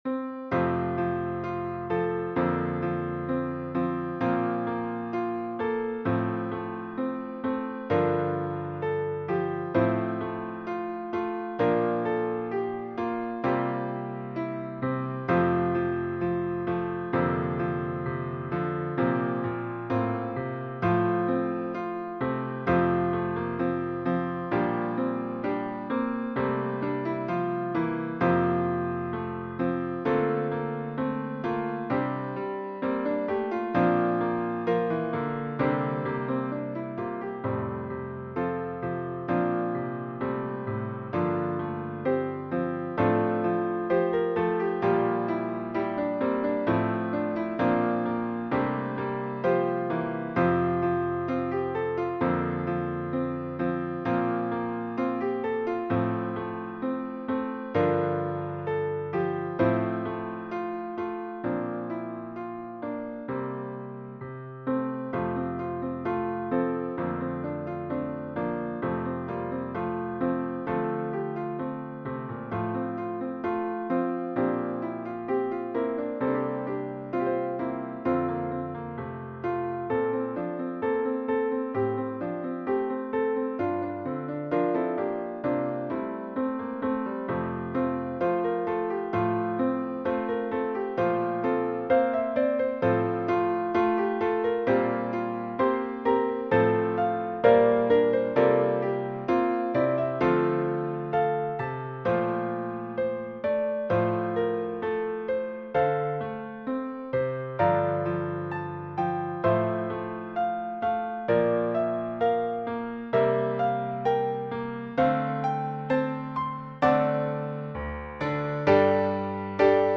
with piano accompaniment
does include two key changes
Voicing/Instrumentation: SATB